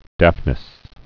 (dăfnĭs)